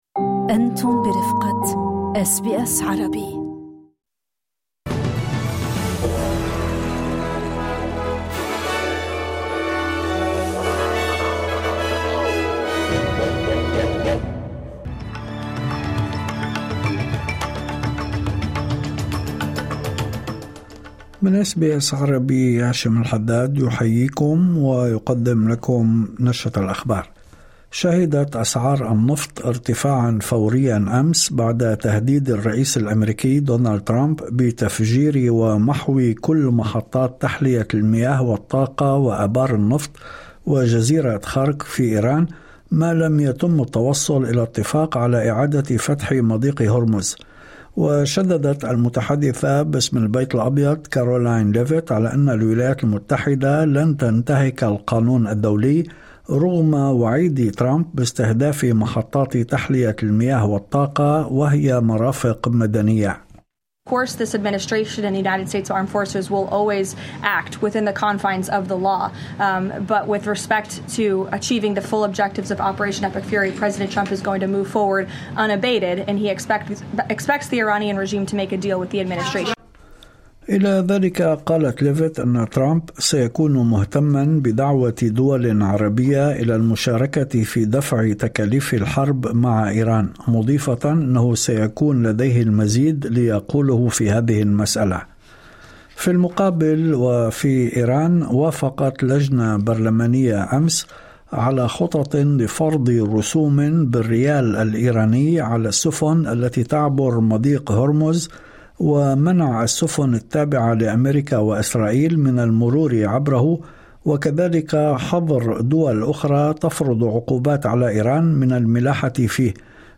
نشرة أخبار الظهيرة 30/03/2026